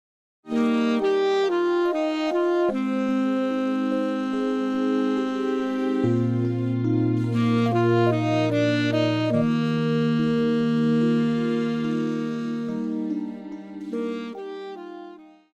流行,宗教
中音萨克斯风
乐团
演奏曲
抒情歌曲
仅伴奏
没有主奏
有节拍器